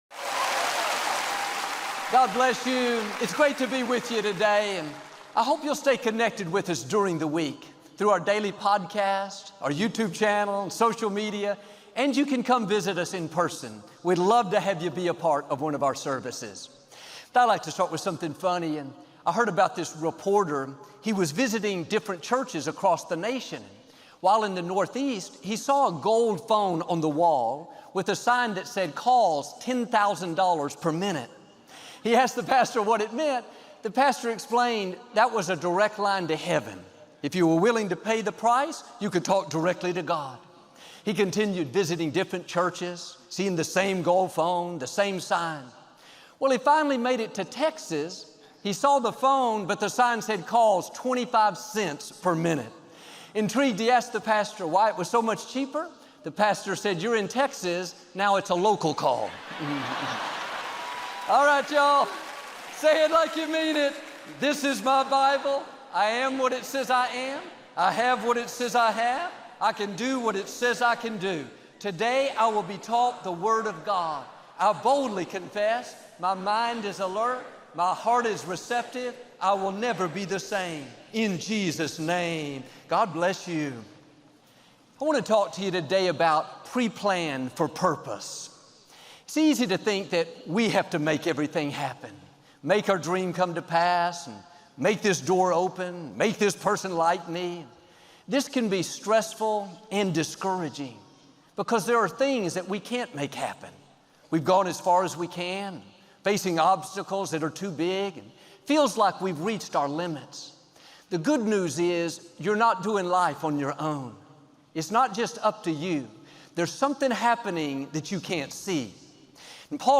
Joel Osteen – Preplanned For Purpose: SERMON - RemaSounds